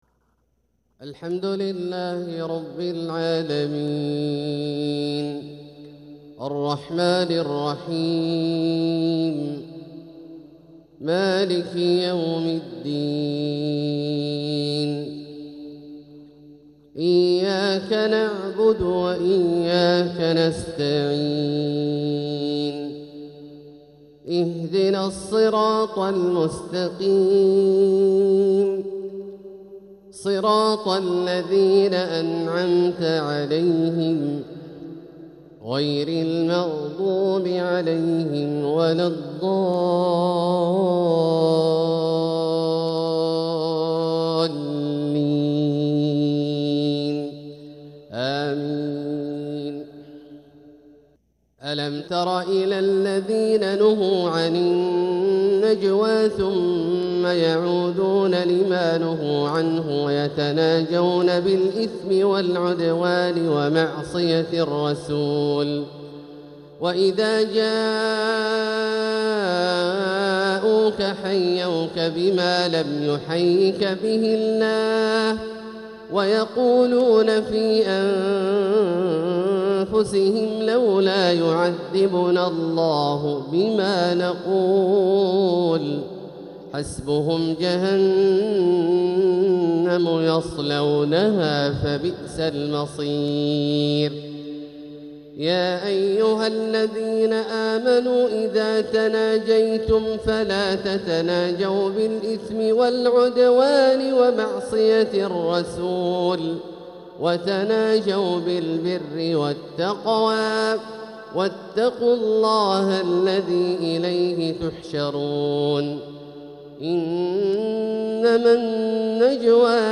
تلاوة من سورة المجادلة | فجر الخميس 1 محرم 1447هـ  > ١٤٤٧هـ > الفروض - تلاوات عبدالله الجهني